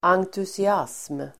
Uttal: [angtusi'as:m (el. en-)]